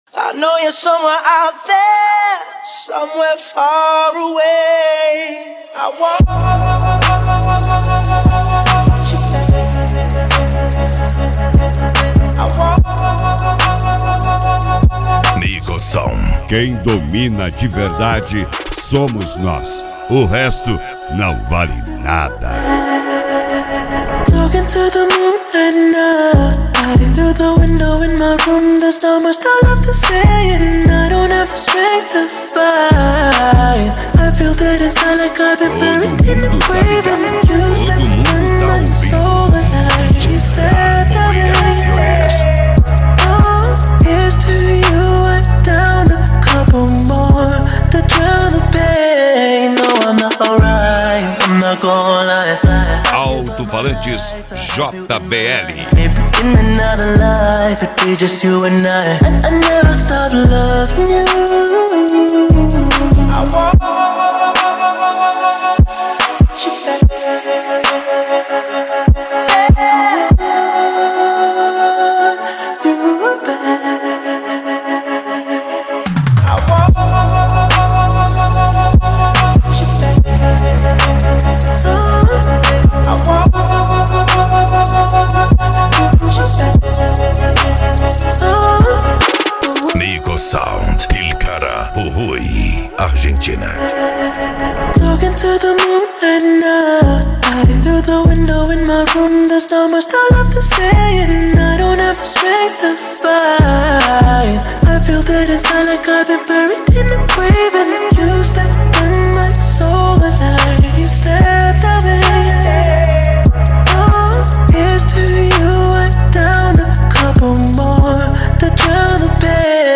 Bass
Electro House
Eletronica
Psy Trance
Remix